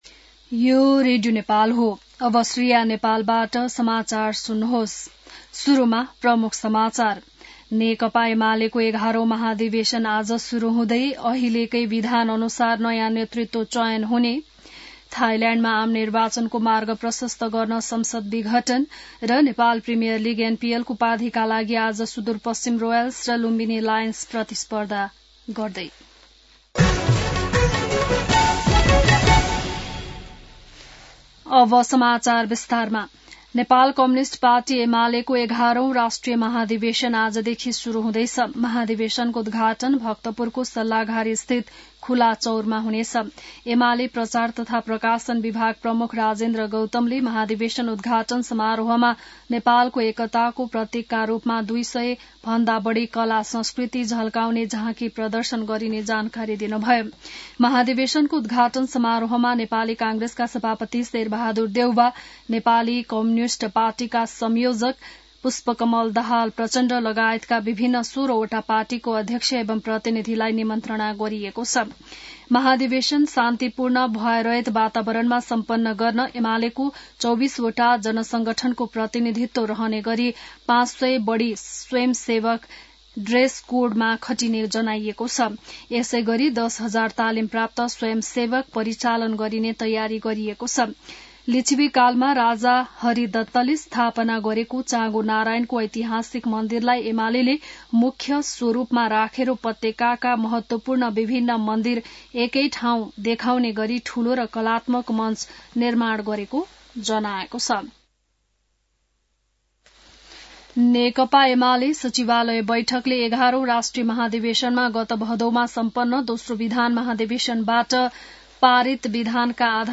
बिहान ९ बजेको नेपाली समाचार : २७ मंसिर , २०८२